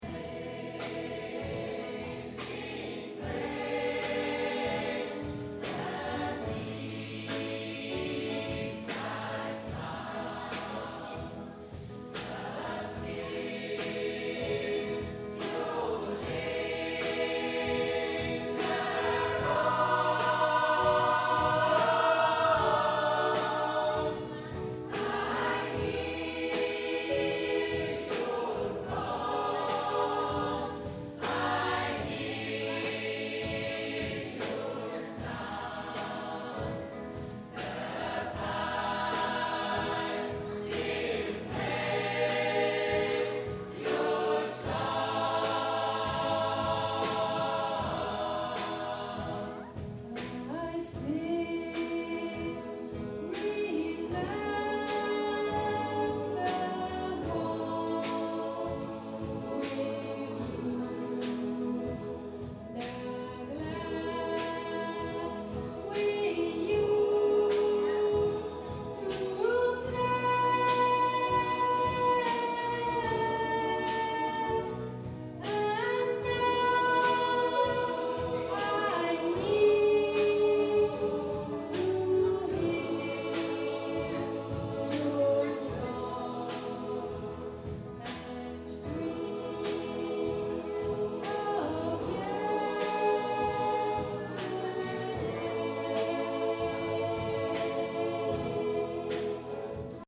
Live-Aufnahmen Konzert Schneisingen 2007
Live-Konzert vom 20./21. Januar 2007 in der kath. Kirche Schneisingen.